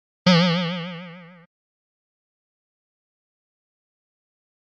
Звуки мультяшной пружины
На этой странице собрана коллекция забавных и узнаваемых звуков мультяшной пружины.
Пружина из мультфильма